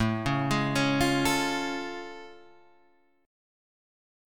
A Augmented Major 7th